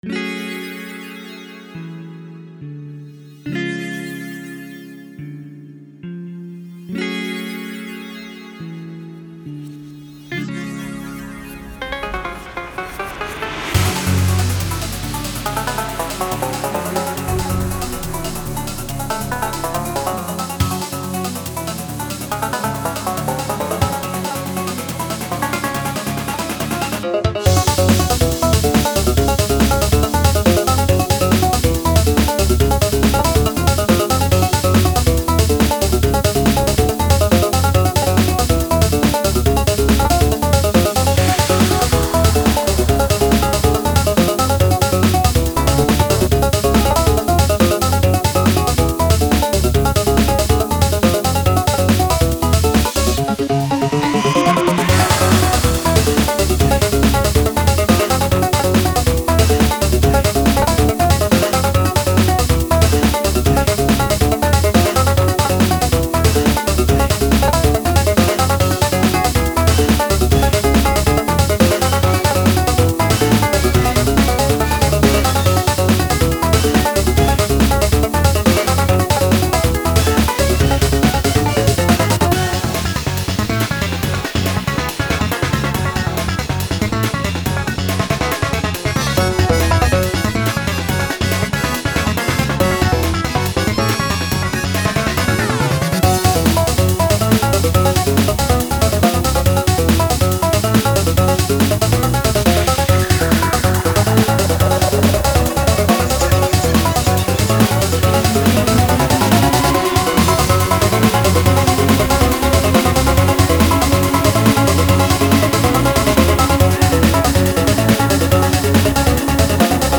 Banjo DnB, inspired by how it feels to eat corn
(Go to 0:52 for yeehaw transition)
rodeo/disco type beat
Drum N Bass